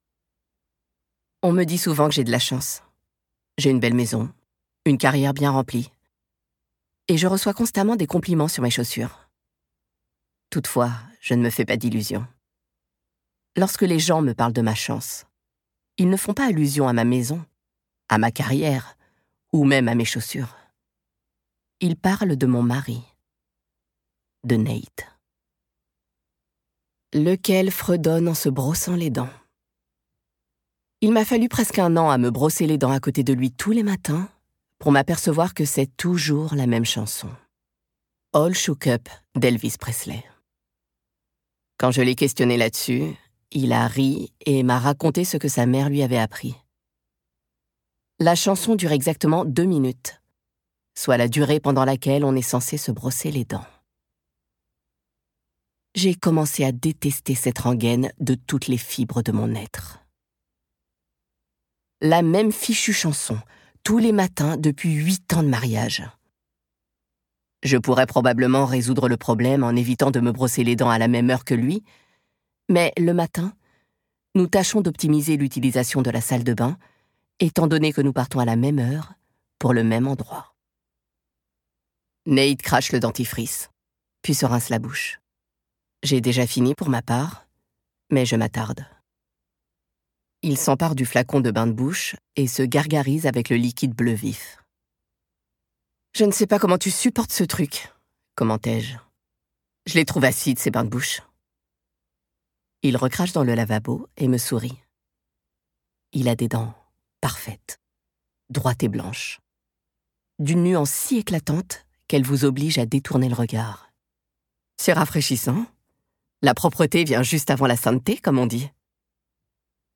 Un thriller psychologique déroutant de Freida McFadden, l'autrice du best-seller La femme de ménage, lu par un trio de comédiens !